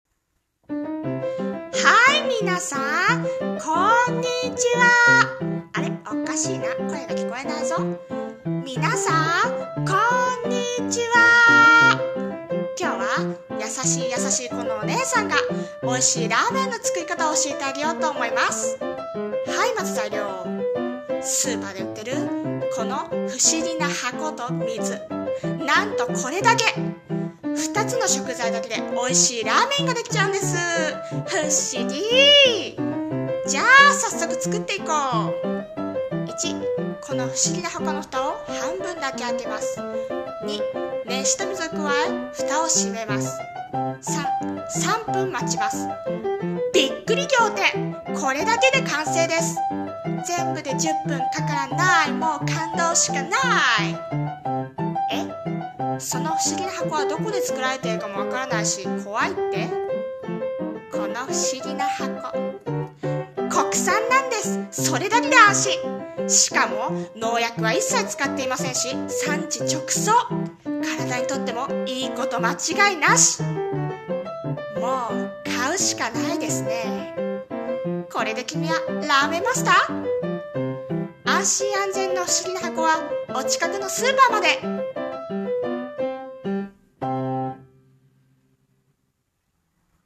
さんの投稿した曲一覧 を表示 【声劇】美味しいラーメンの作り方